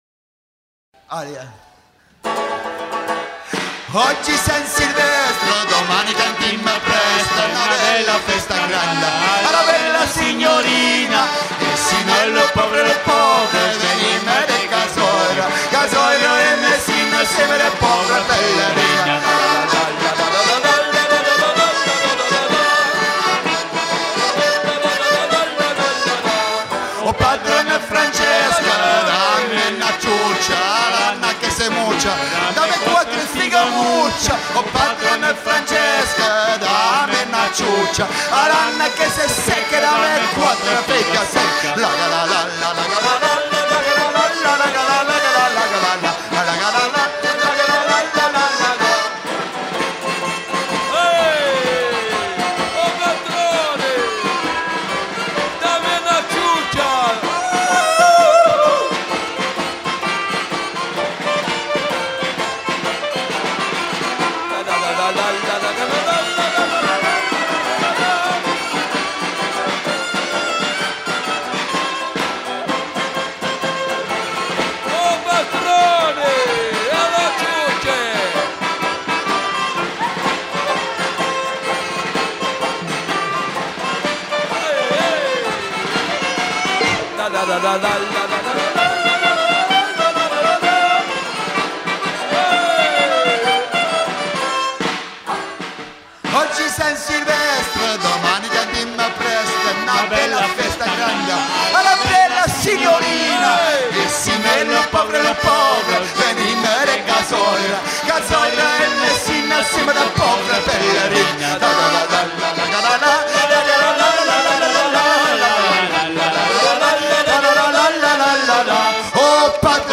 chant de la veille du jour de l'an
Genre strophique
Pièce musicale éditée